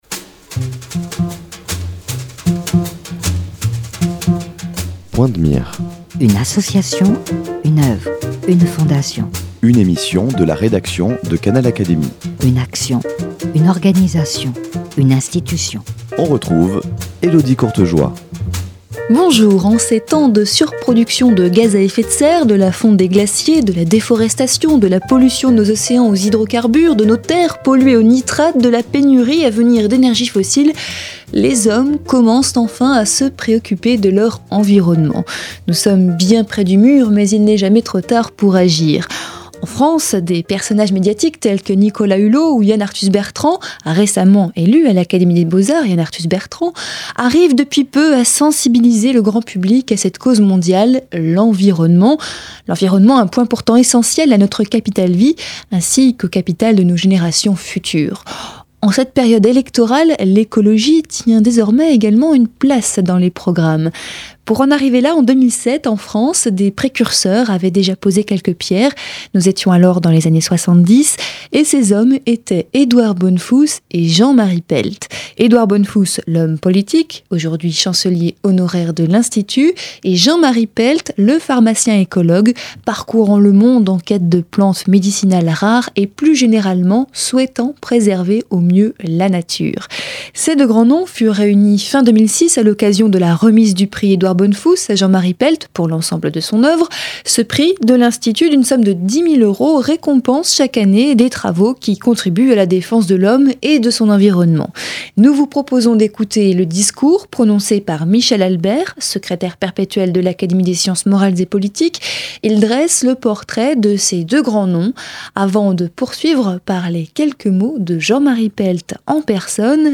L’écologue de renom Jean-Marie Pelt a reçu le prix Bonnefous 2006 pour l’ensemble de son oeuvre. Canal Académie retransmet cette remise de prix qui a eu lieu à l’Institut de France, à Paris.